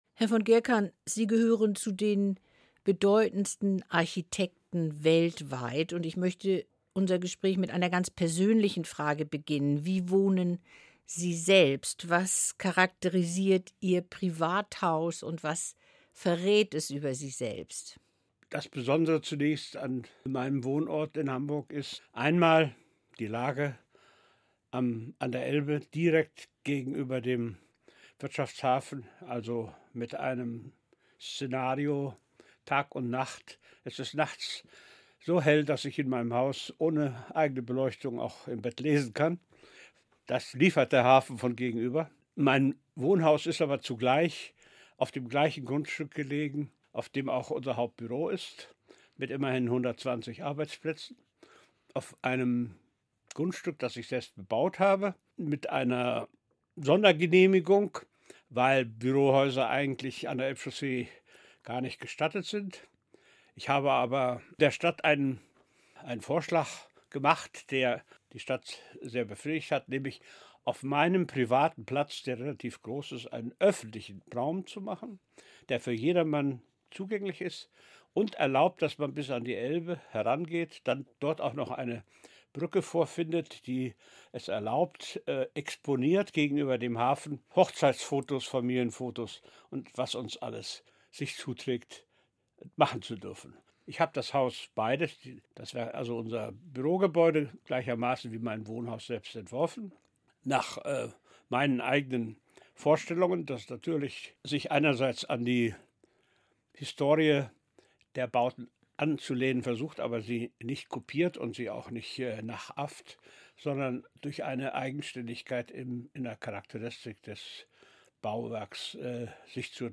Erika Pluhar im Gespräch